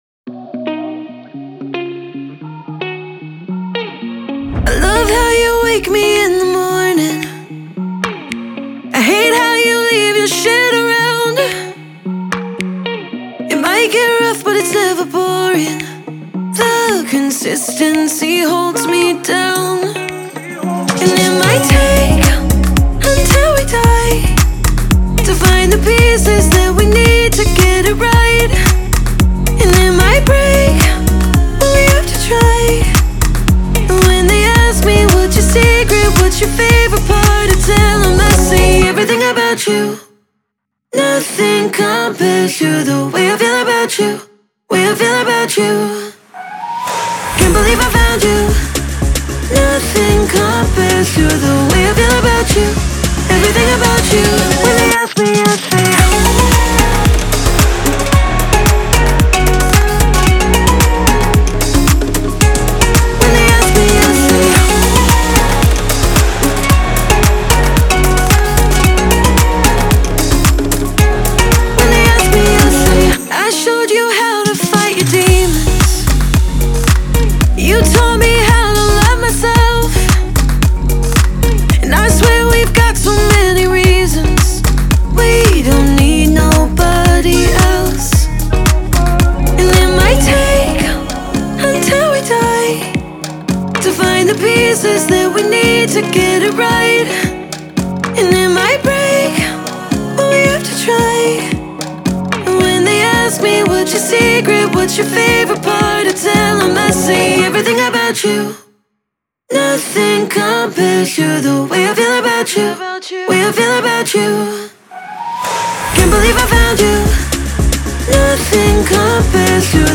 это энергичная трек в жанре дип-хаус